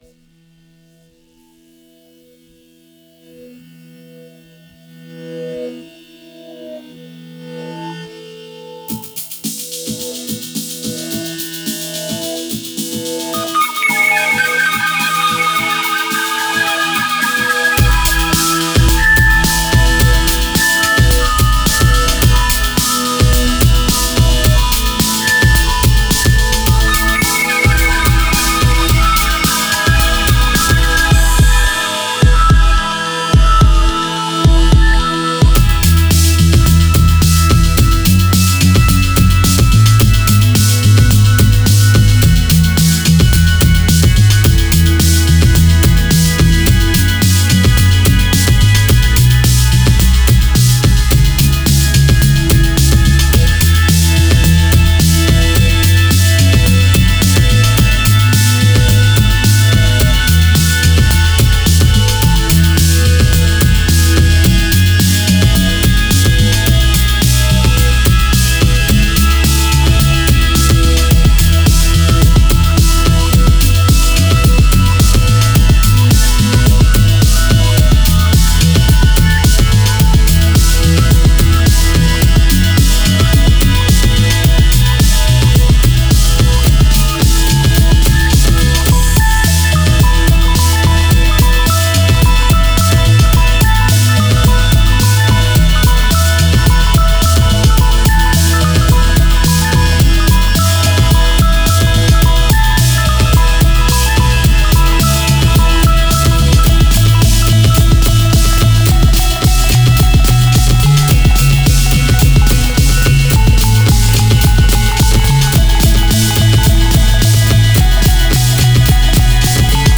oscillant entre trip hop et techno pop